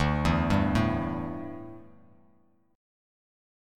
Dsus2sus4 chord